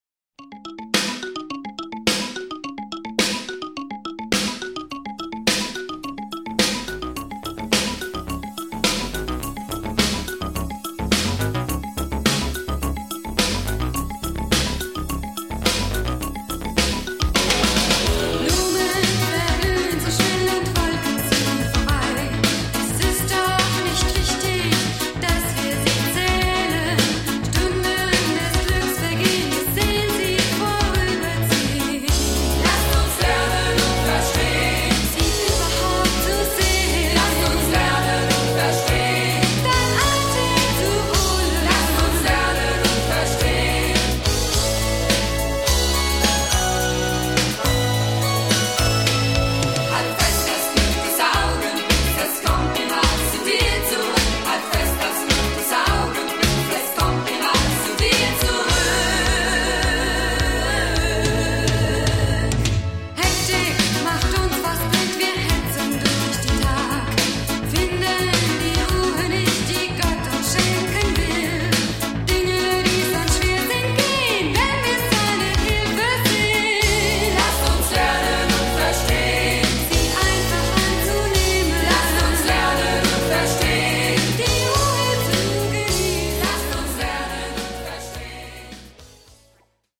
rockige Sounds
Pop